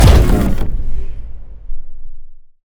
droidic sounds
hurt1.wav